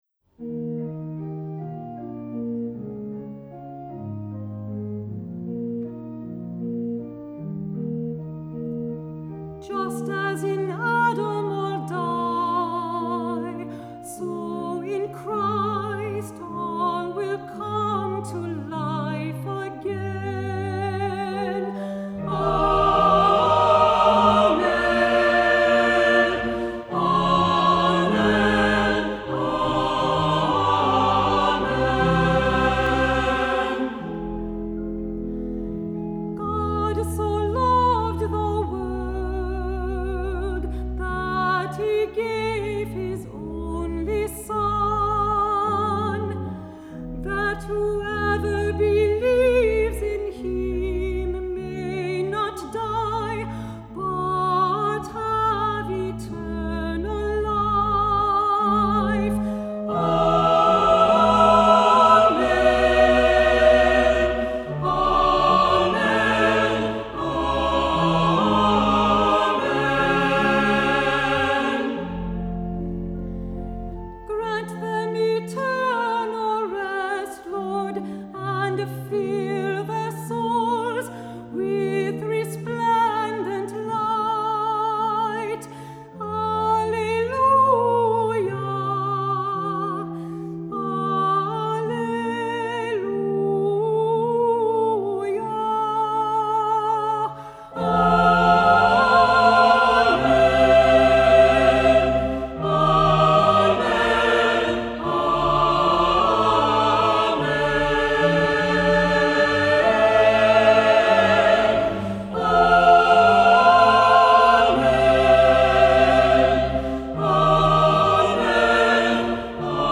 Accompaniment:      Organ
Music Category:      Christian